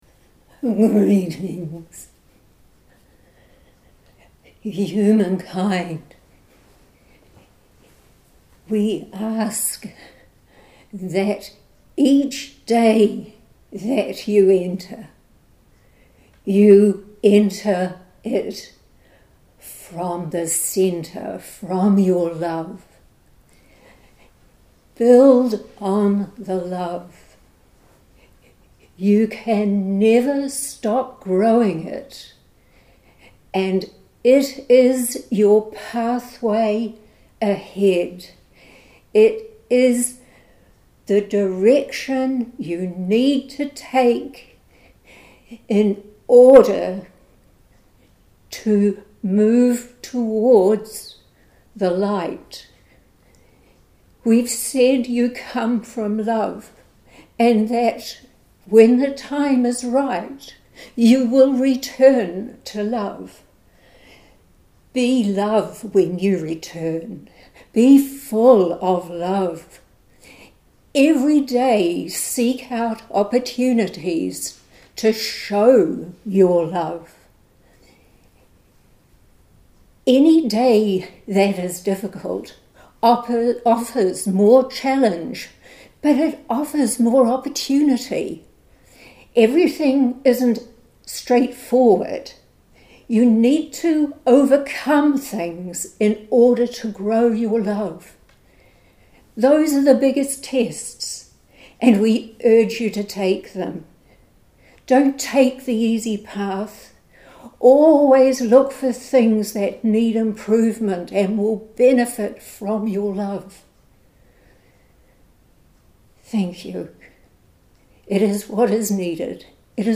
“Grow Your Love” – audio recording of channelled message from The Circle of The Light of The Love Energy